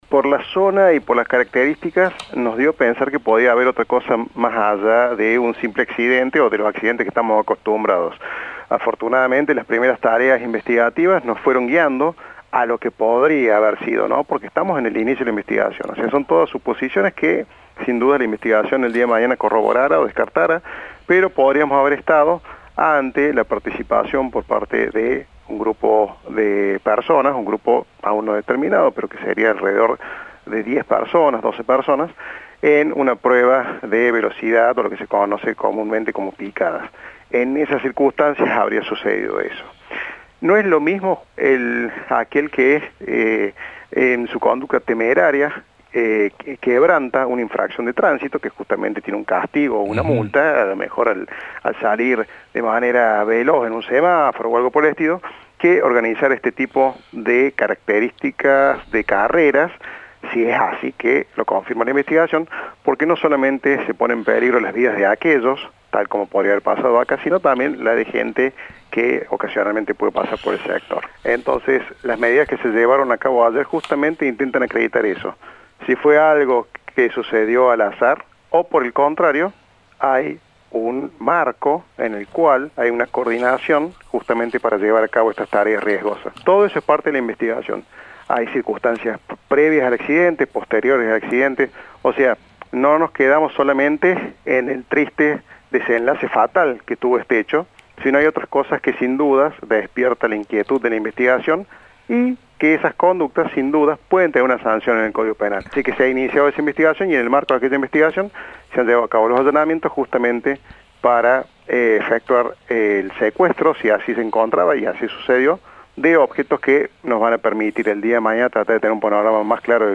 El fiscal habló sobre el hecho que dejó dos jóvenes fallecidos.